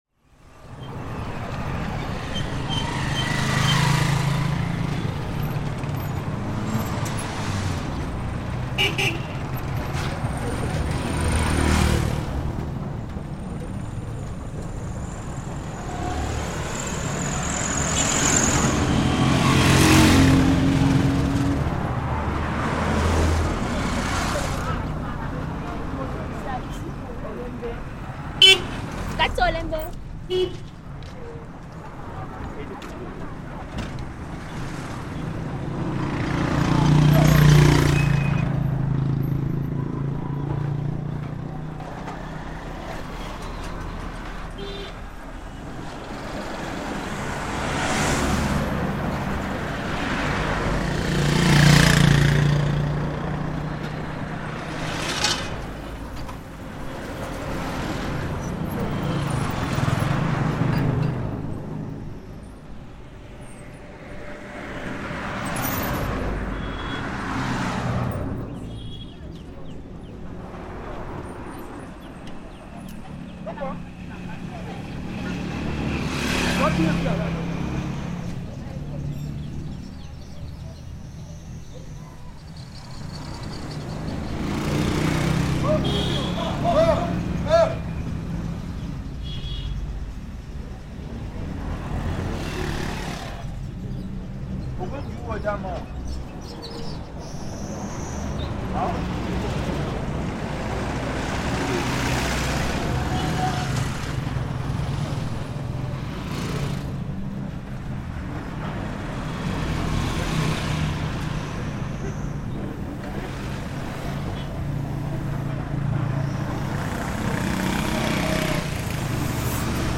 Intermundium is a stroll down the sidewalk in a street called Titi Garage in Yaounde, Cameroon (Central Africa). This piece was recorded using stereo microphones. On the right, you can hear the hustle and bustle of the road: mostly cars and motorcycles zooming by.
On the left, you can hear folks chatting, taxis stopping, and bars with music playing. Amidst this urban soundscape, the natural world is subtly integrated, with the chirping of birds from the trees overhead serving as a gentle backdrop. This piece shows the contrast between two completely different worlds and the experience of existing on the boundary between them - The Intermundium.